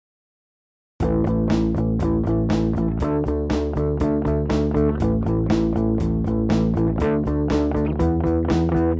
Rhythm part with a standard bassline beneath
Rhythm example, normal bassline